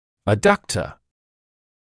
[ə’dʌktə][э’дактэ]аддуктор, приводящая мышца